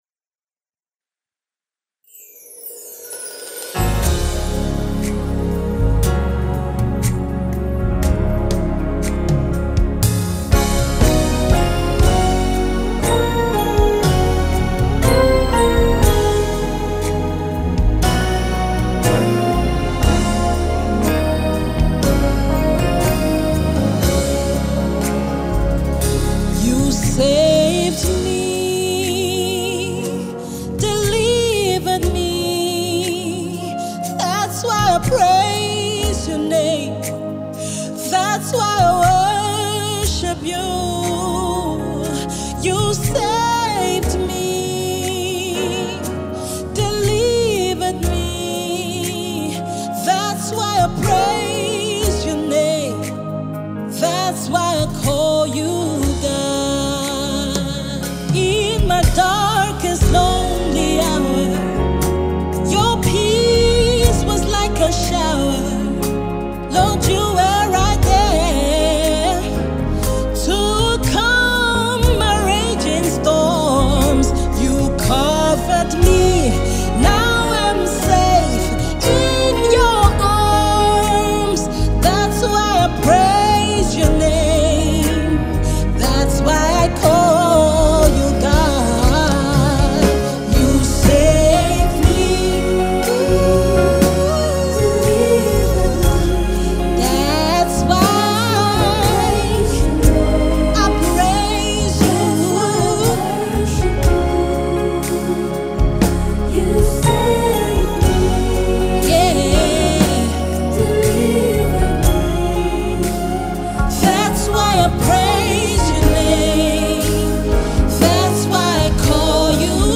reflective and appreciative mode.